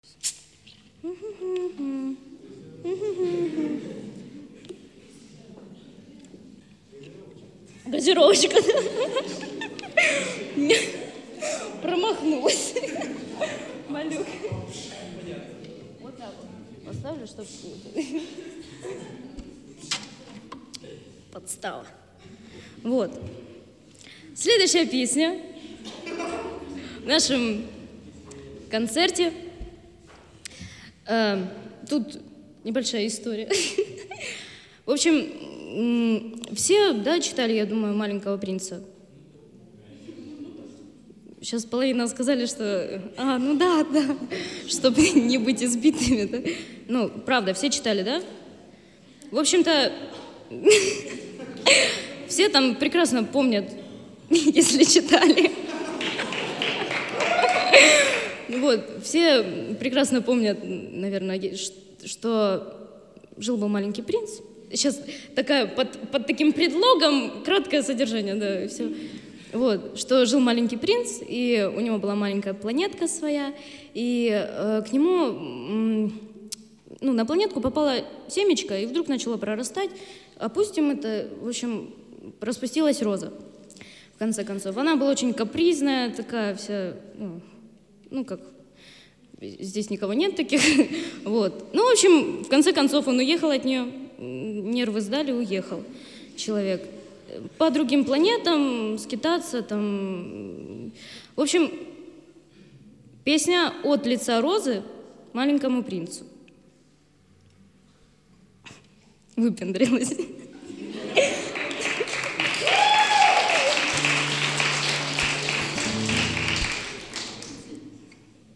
записанный на концерте пятого мая в галерее "Юг", г. Краснодар